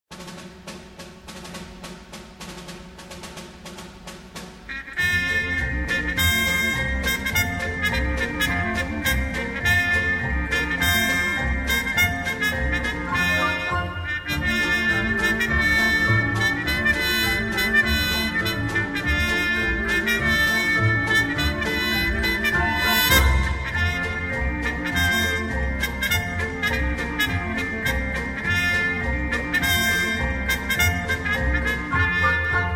Brass Timbre test question 7